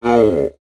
giant5.wav